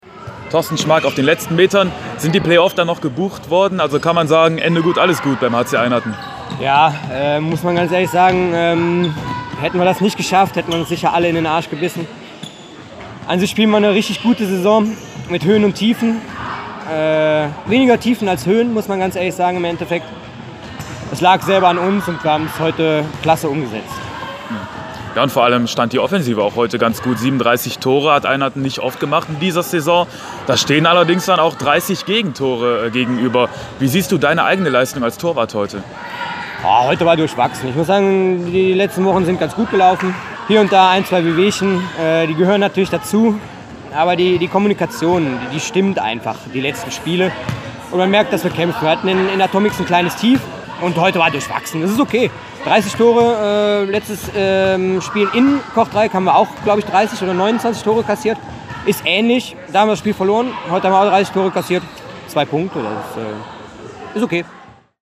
Der HC Eynatten-Raeren hat das erhoffte Play-off-Ticket in der 1.Division gelöst. Mit einer couragierten und konzentrierten Leistung schlugen die Rot-Schwarzen Kortrijk mit 37:30. Nach dem Abpfiff